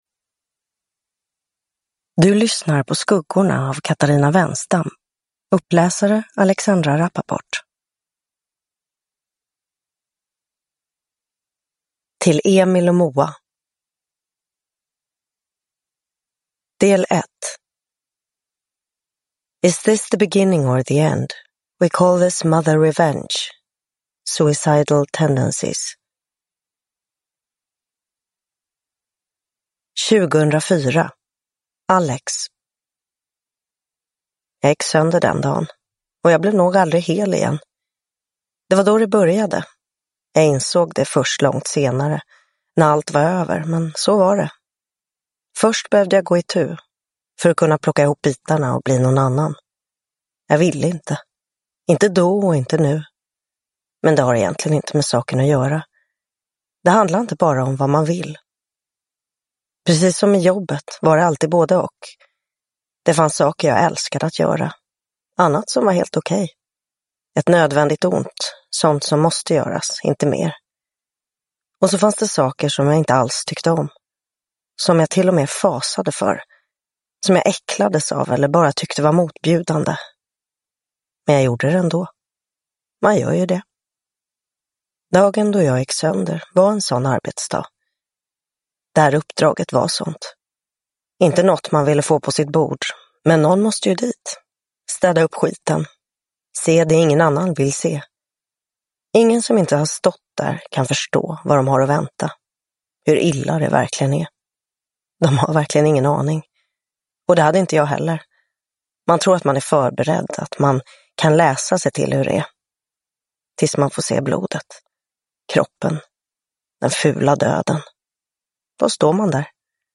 Uppläsare: Alexandra Rapaport
Ljudbok